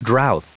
Prononciation du mot drouth en anglais (fichier audio)
Prononciation du mot : drouth